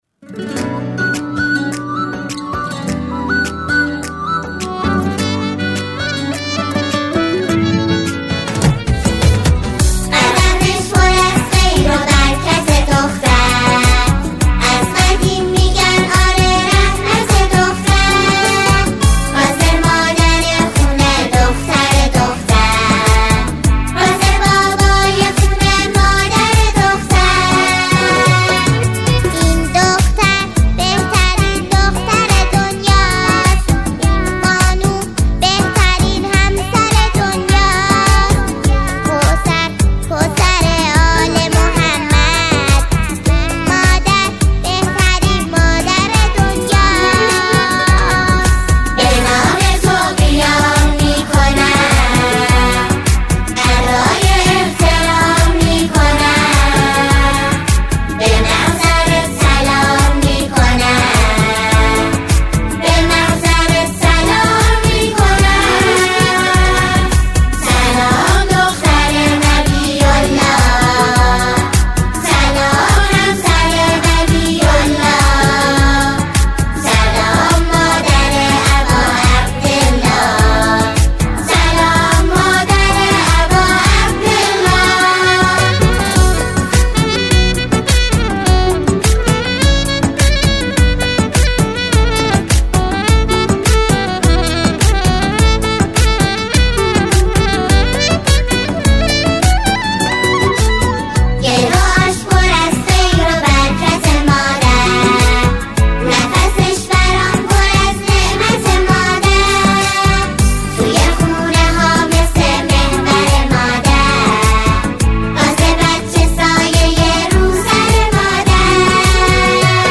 با اجرای مشترک و شادمانه